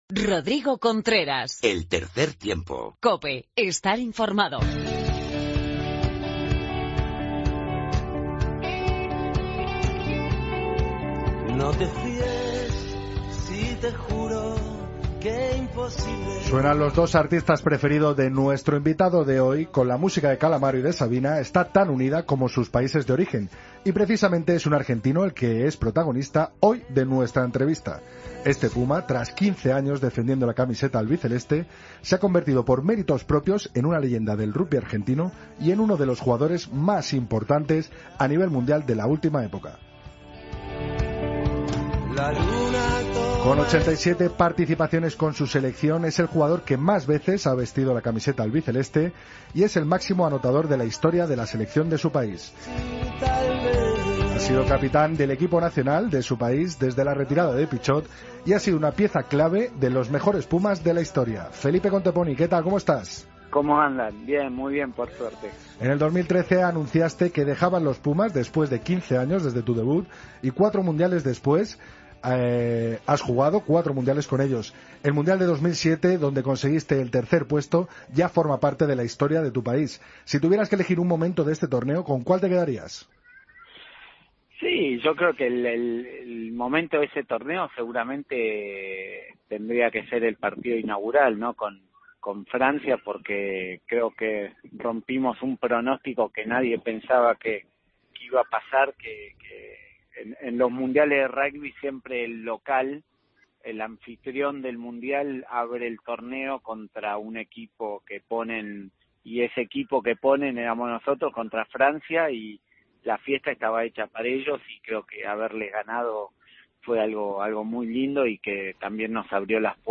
Entrevista a Felipe Contepomi